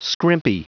Prononciation du mot scrimpy en anglais (fichier audio)
Prononciation du mot : scrimpy